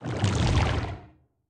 Sfx_creature_bruteshark_swim_slow_07.ogg